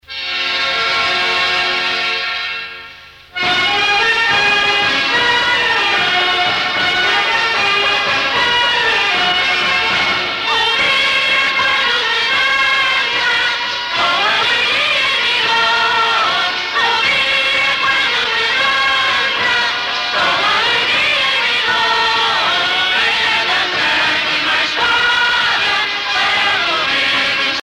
danse : vira (Portugal)
Pièce musicale éditée